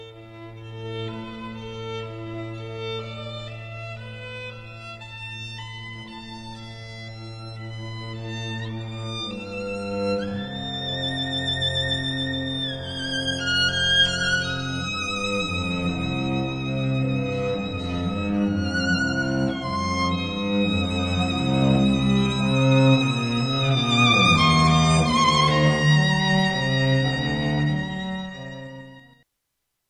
duo_vi-oline_violo-nche-llo.mp3